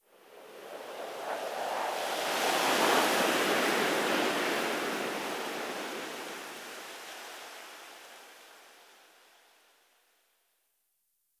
windwhistle6.ogg